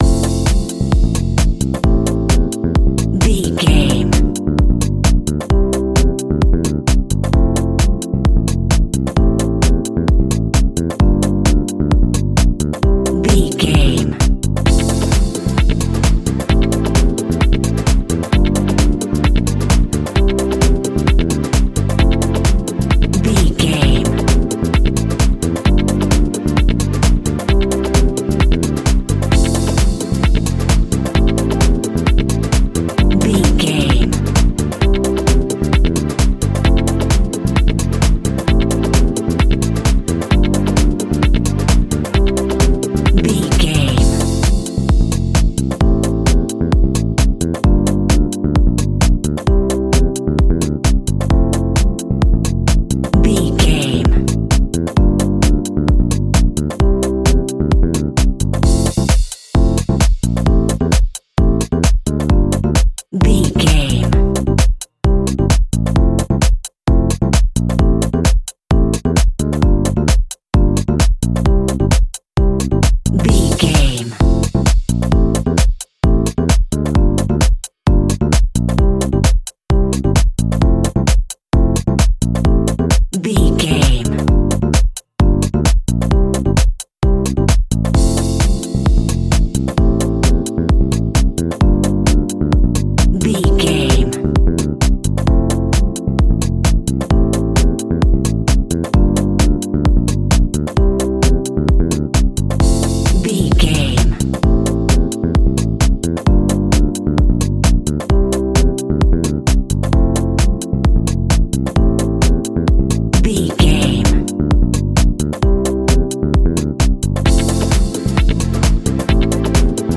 Ionian/Major
groovy
uplifting
energetic
bass guitar
brass
saxophone
drums
electric piano
electric guitar
electro
disco
synth
upbeat
instrumentals